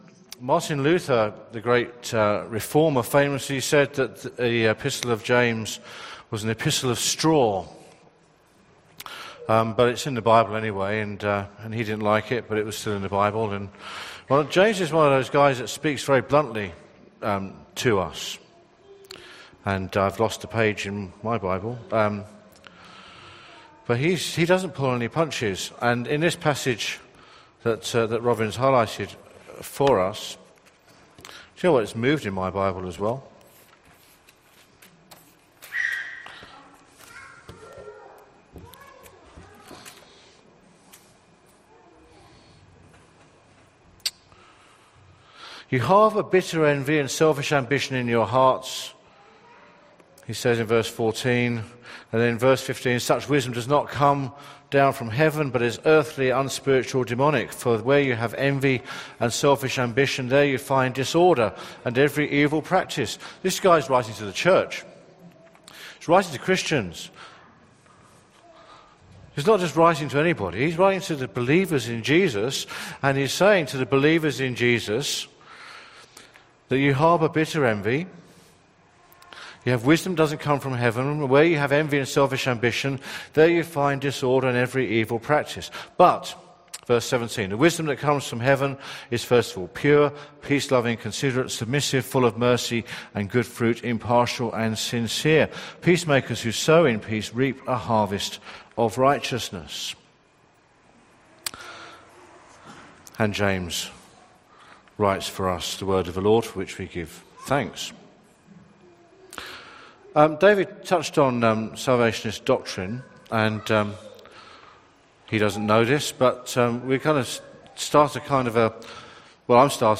Sermon from the 11:00 meeting on 20th September at Newcastle Worship & Community Centre. Centred on James 3:13-4:3.